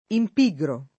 imp&gro] agg. — latinismo poet. («operoso; fervente»), ripreso dal lat. impiger [&mpiJer], genit. impigri [&mpigri], ma trattato dai poeti it. come parola piana, quasi una pura e semplice negaz. di pigro